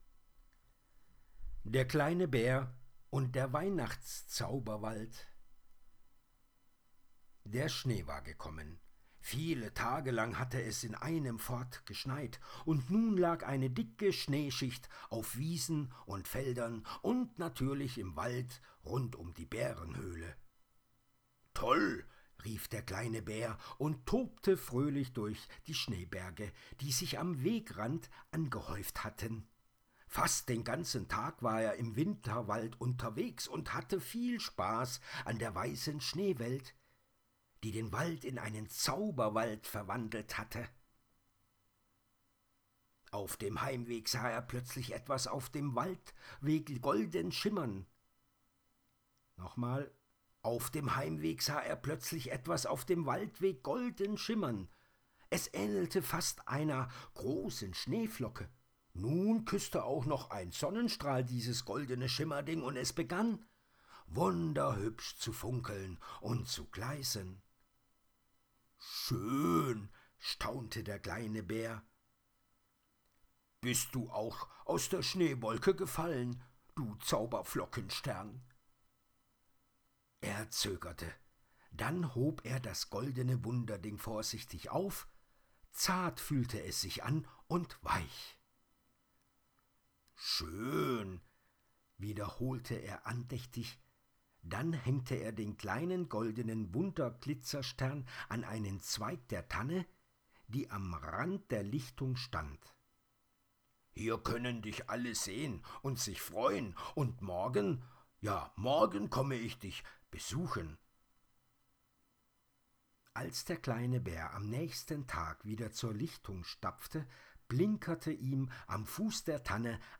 Adventsmärchen für Kinder